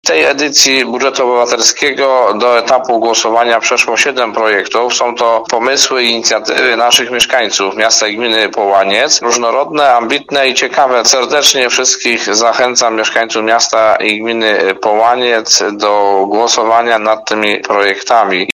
Mówi burmistrz Połańca Jacek Benedykt Nowak: